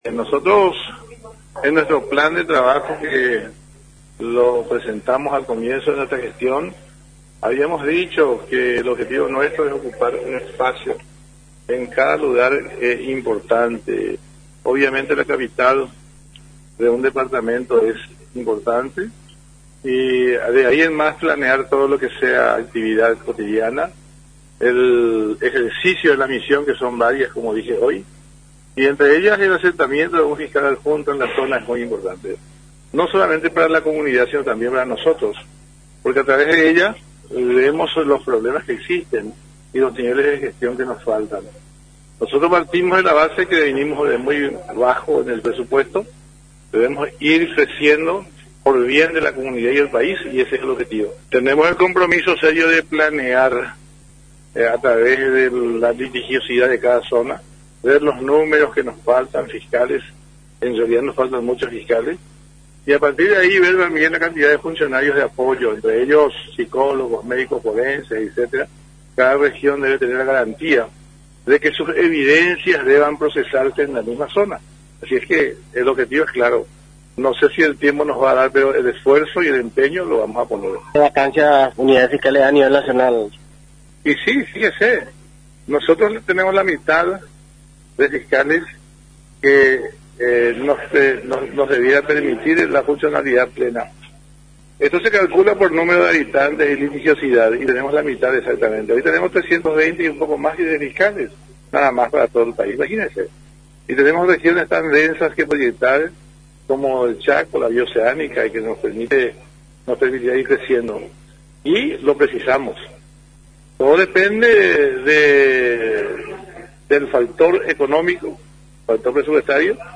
En la ceremonia de asunción desarrollada en la Fiscalía Regional de San Pedro de Ycuamandyyú, el titular de la FGE celebró la incorporación de la nueva fiscal adjunta, quien tendrá la ardua tarea constitucional e institucional de velar por los intereses de la ciudadanía sampedrana.
EDITADO-17-DR.-EMILIANO-ROLON-FISCAL-GENERAL.mp3